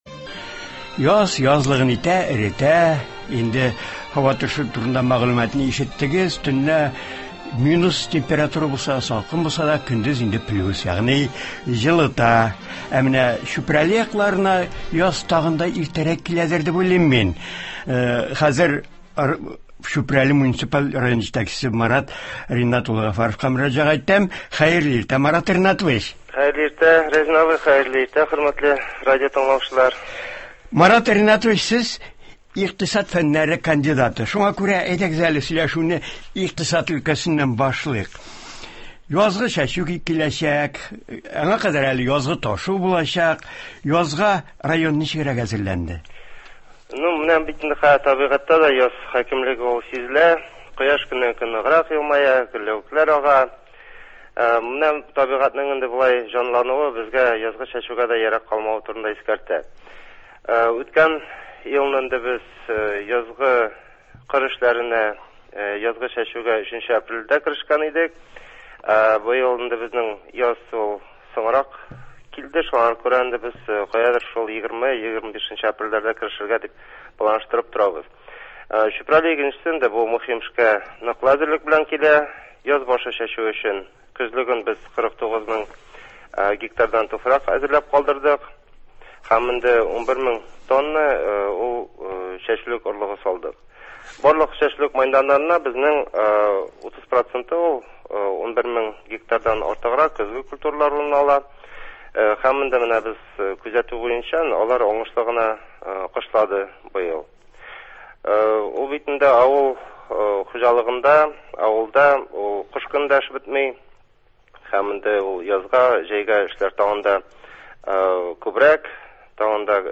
Быелгы яз Чүпрәле муниципаль районына нинди яңалыклар алып килә? Күпмилләтле төбәктә Туган телләр һәм халыклар бердәмлеге елын нинди чаралар белән үткәрәләр? Болар хакында телефон элемтәсе аша район җитәкчесе Марат Гафаров сөйли.